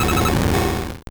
Cri de Qulbutoké dans Pokémon Or et Argent.